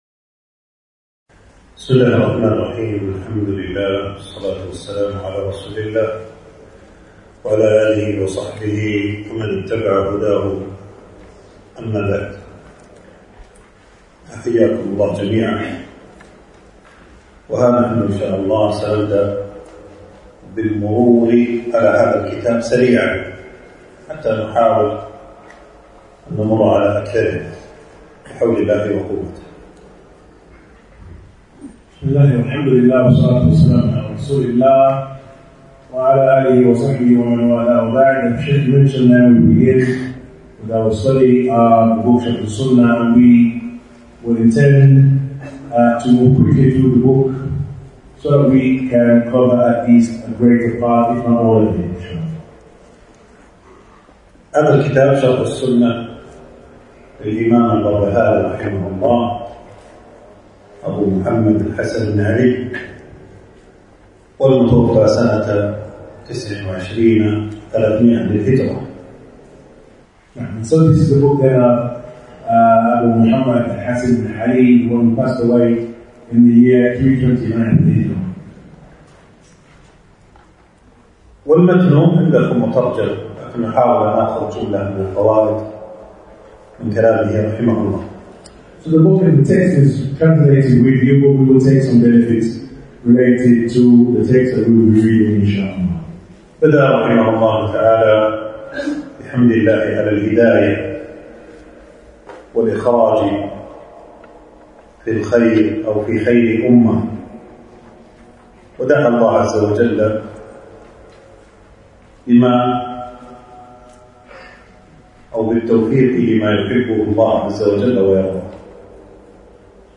تنزيل تنزيل التفريغ العنوان: شرح، شرح السنة للبربهاري. (الدرس الأول) ألقاه
المكان: درس ألقاه يوم السبت 17جمادى الأول 1447هـ في مسجد السعيدي.